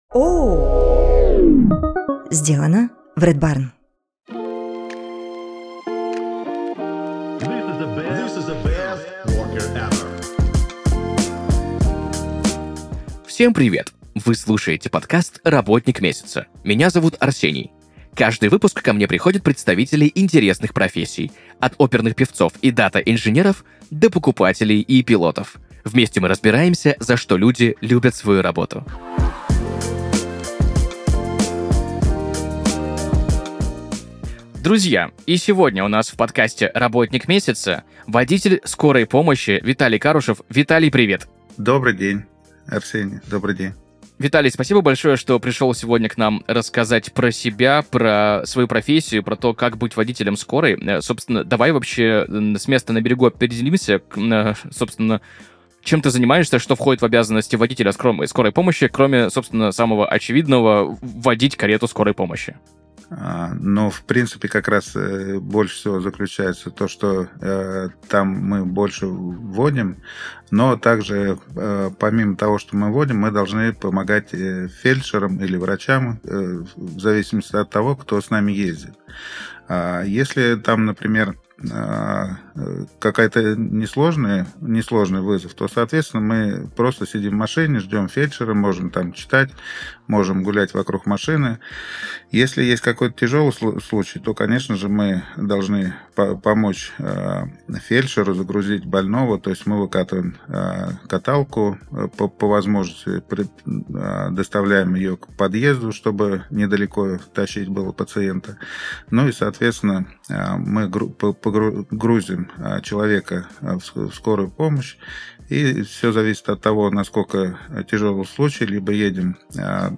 Приглашаем в студию представителей интересных профессий – от астрономов и оперных певцов до кузнецов и фрахтовых брокеров – и просим раскрыть «внутреннюю кухню» их работы.
voditel_karety_skoroj_pomoshhi_rabotnik_mesyatsa.mp3